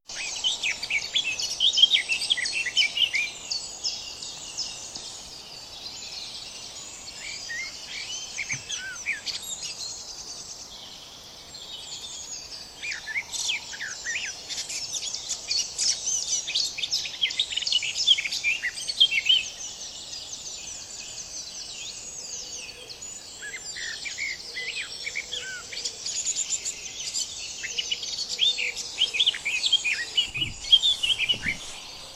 Dawn Chorus 2020
Last Sunday I took my lock-down exercise in the wood at 5am so I could record some of the dawn chorus.
This one is a very tuneful blackcap:
Info for those of a technical bent: I use audio-technica AT829 stereo microphones with a Sound Professionals SP-SPSB-1 microphone power supply and an iAudio7 mp3 recorder.
I post-process the recordings using an audio editor to amplify the signal and apply some noise reduction. It’s unfortunate that the noise from the motorway is way too easily picked up, and all the more surprising for very early on a Sunday morning during lock-down.
ENC0010-blackcap.mp3